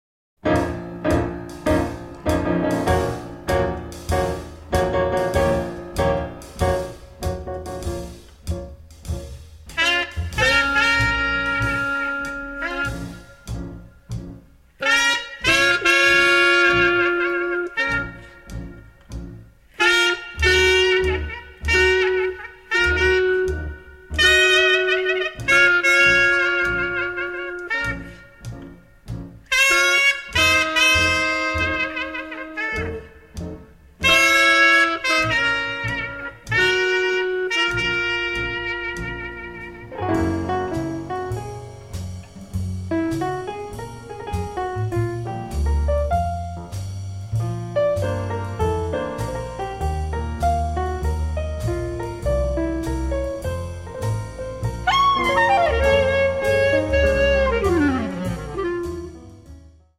60s Jazz Music Vinyl